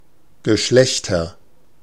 Ääntäminen
Ääntäminen Tuntematon aksentti: IPA: /ɡəˈʃlɛçtɐ/ Haettu sana löytyi näillä lähdekielillä: saksa Käännöksiä ei löytynyt valitulle kohdekielelle. Geschlechter on sanan Geschlecht monikko.